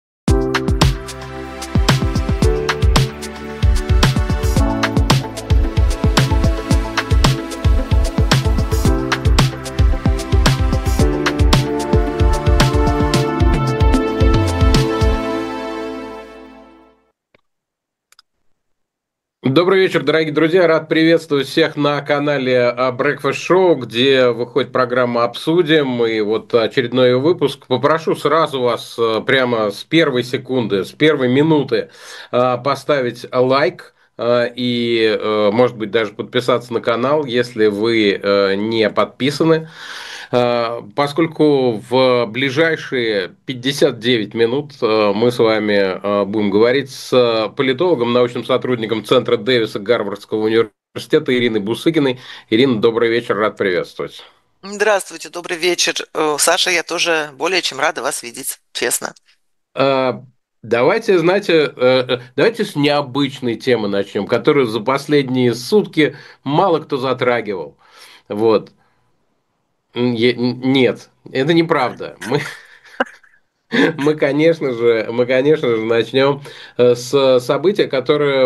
Эфир ведёт Александр Плющев